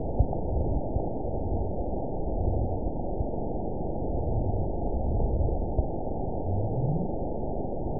event 917334 date 03/28/23 time 04:33:03 GMT (2 years, 1 month ago) score 9.64 location TSS-AB01 detected by nrw target species NRW annotations +NRW Spectrogram: Frequency (kHz) vs. Time (s) audio not available .wav